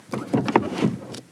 Palanca de cambios de un coche Golf 1
palanca de cambios
Sonidos: Transportes